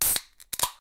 can_open2.ogg